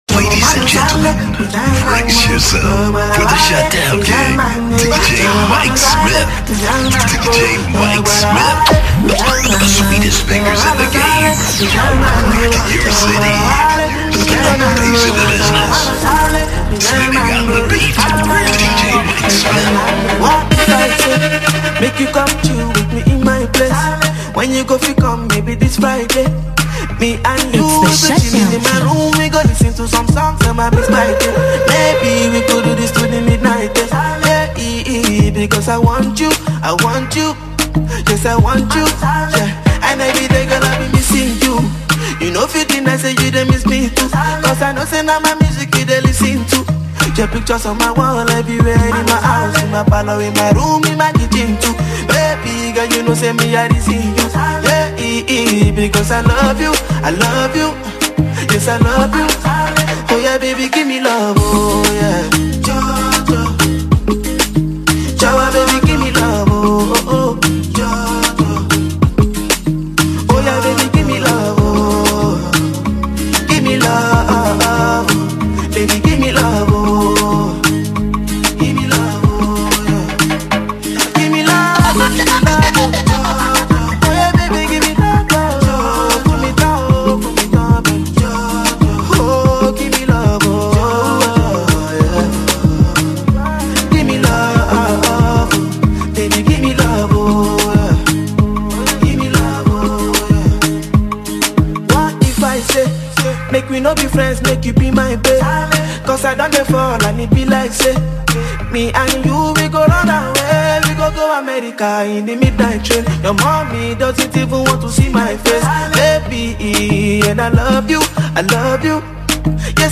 Genre: Mixtape